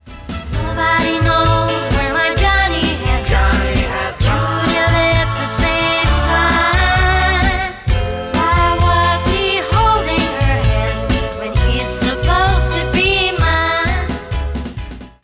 Tonalidad: La mayor.
Duración: 8 compases 4/4.
El acorde B refuerza, como V/V, la semicadencia final.